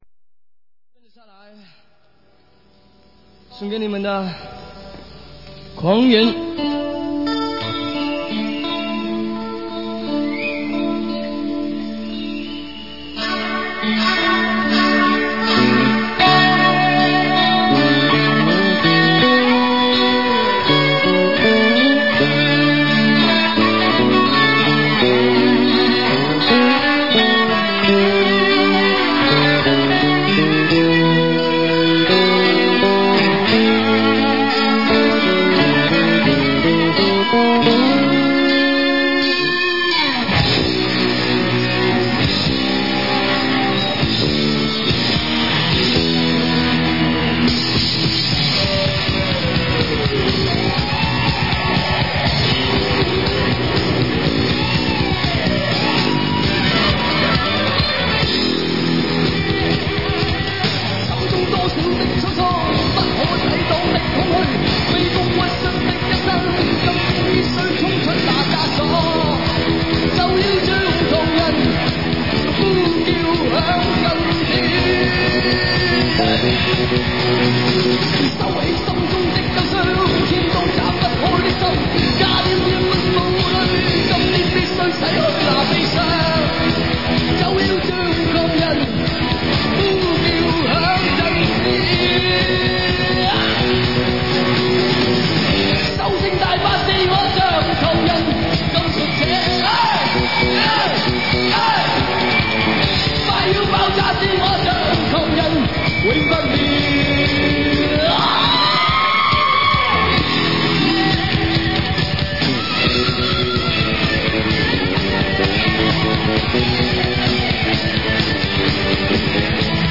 1986年【台北演唱会】